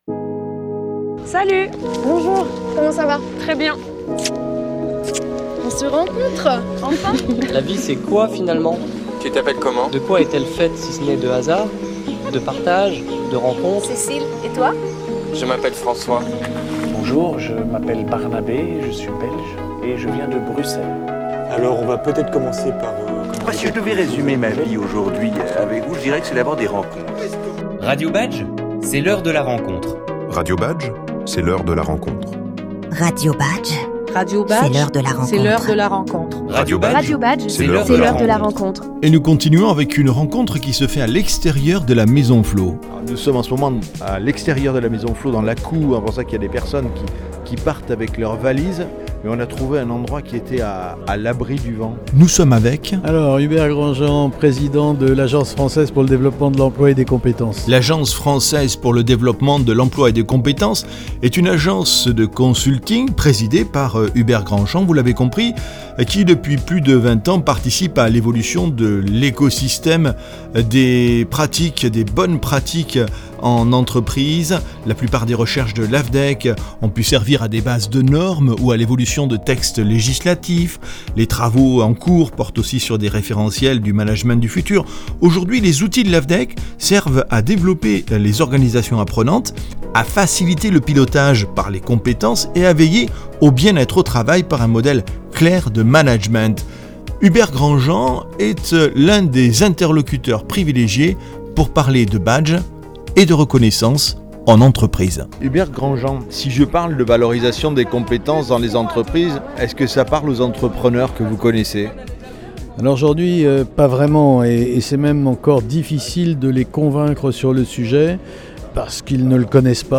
Lors du Forum de Vannes